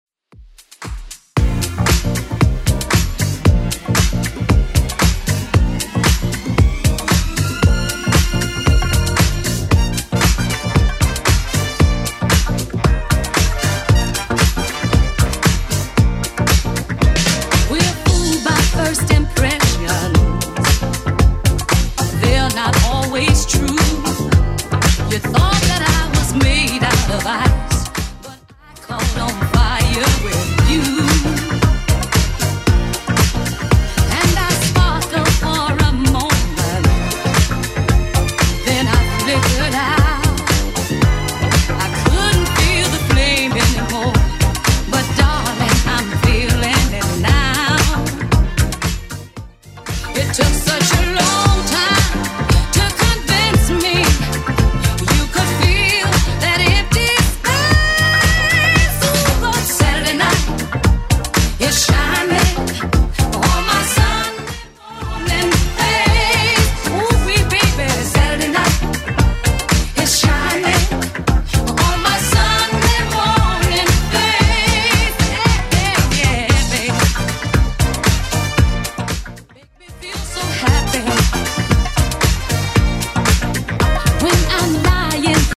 BPM: 115 Time